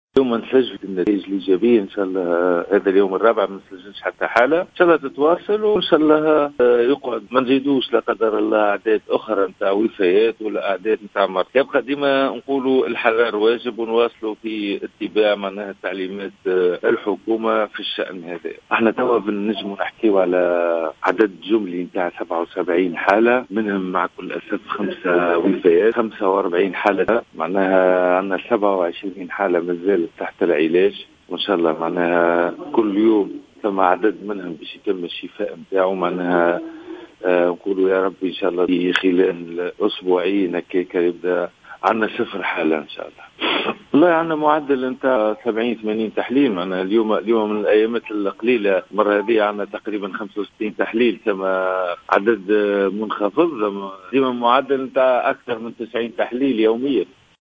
وأضاف الرقيق في تصريح للجوهرة أف أم، أن إجمالي عدد الحالات المُسجلة في ولاية سوسة 77 حالة منها 45 حالة تماثلت للشفاء و 27 حالة مازالت تحت العلاج و5 وفيات.